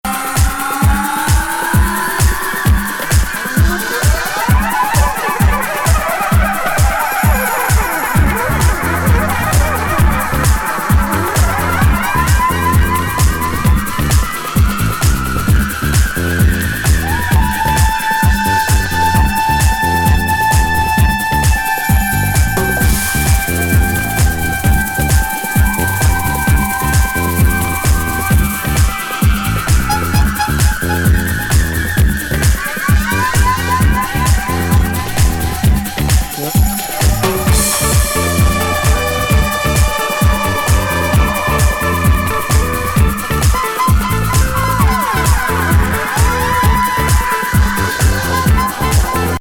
土着的リズムとディスコ・グルーヴの入り乱れる素晴らしい一枚!上音の飛びが